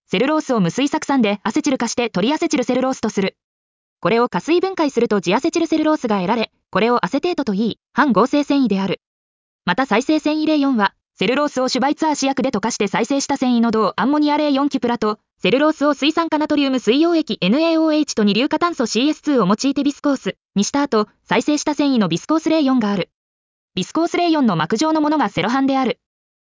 • 耳たこ音読では音声ファイルを再生して要点を音読します。